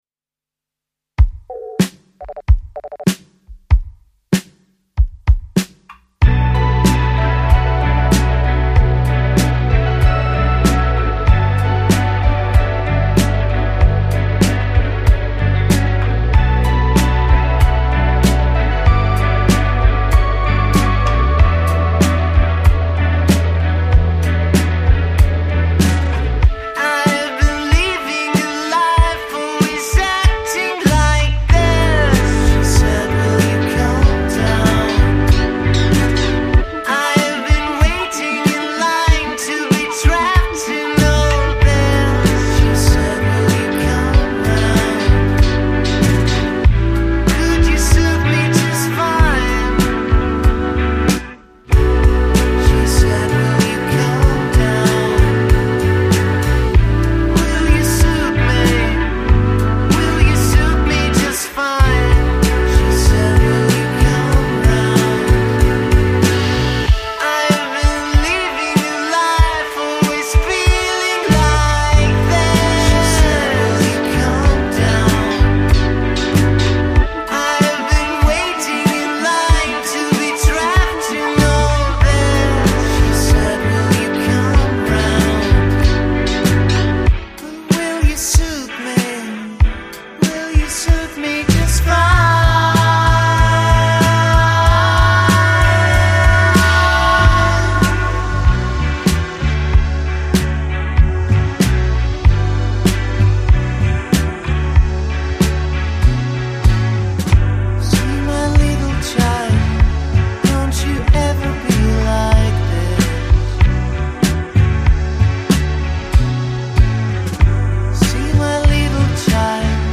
è un cantautore nato a Firenze.